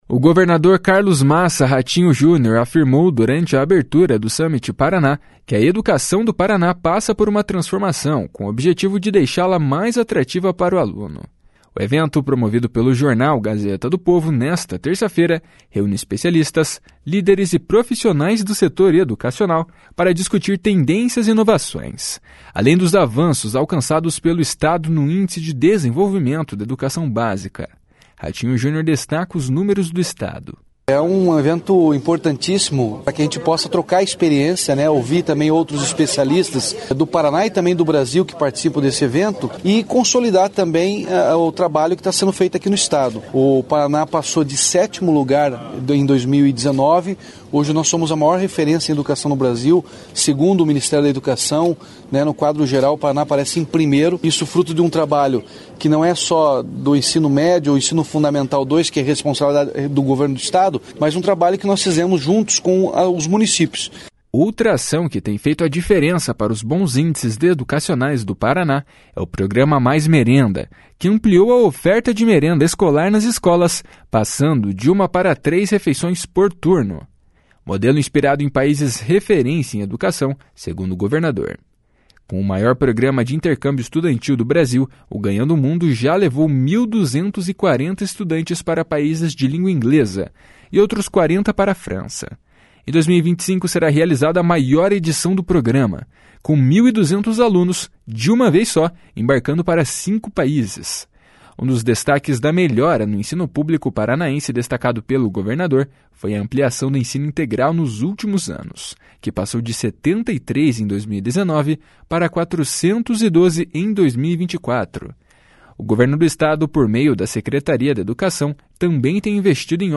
O governador Carlos Massa Ratinho Junior afirmou durante a abertura do Summit Paraná que a educação do Paraná passa por uma transformação com o objetivo de deixá-la mais atrativa para o aluno.
Ratinho Junior destaca os números do Estado. // SONORA RATINHO JUNIOR //